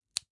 胡萝卜
Tag: 裂纹 流行 胡萝卜 卡扣